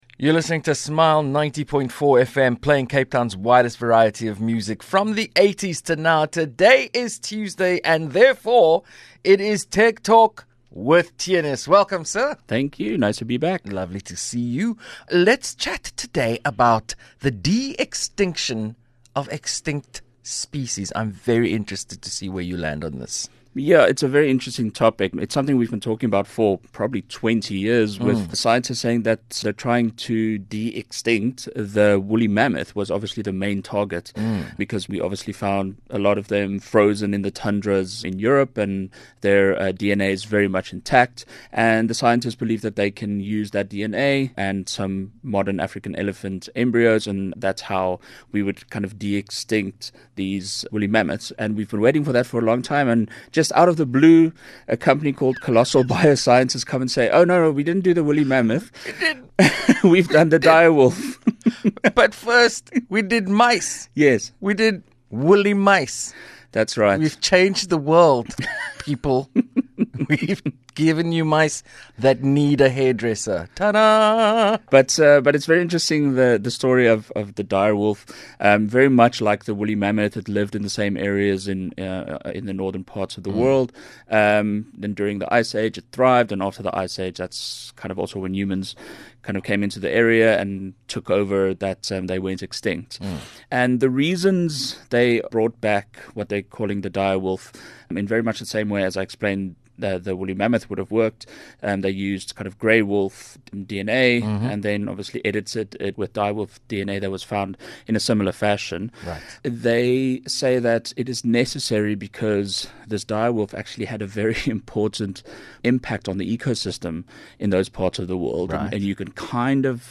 Stay tuned for a conversation that walks the line between science fiction and the frontier of biotechnology.